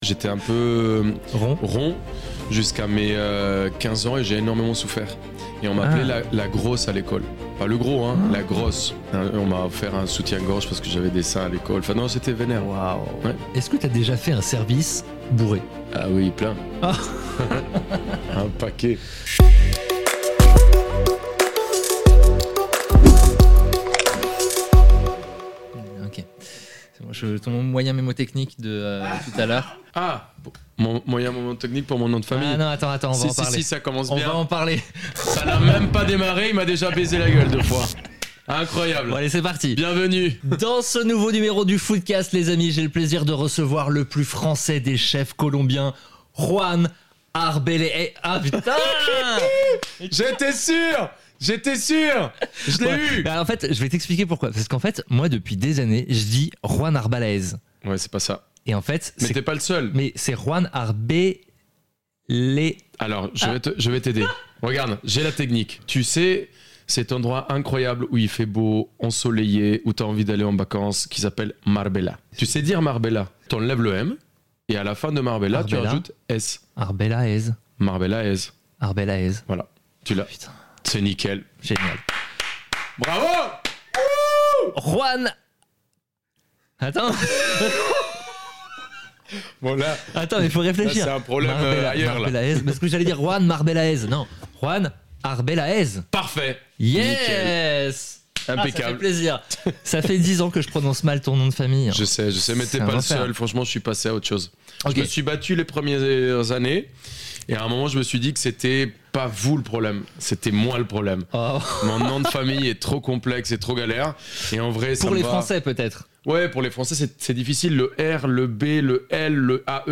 J’ai reçu sur mon canapé le chef star Juan Arbelaez qui est revenu sur son parcours parsemé de harcèlement, de violence et de réussite !